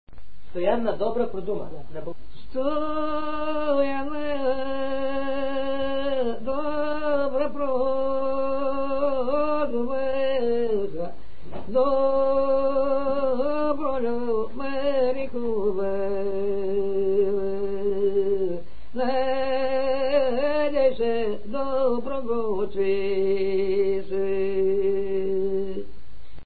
музикална класификация Песен
размер Безмензурна
фактура Едногласна
начин на изпълнение Солово изпълнение на песен
битова функция На попрелка
фолклорна област Югоизточна България (Източна Тракия с Подбалкана и Средна гора)
място на записа Харманли
начин на записване Магнетофонна лента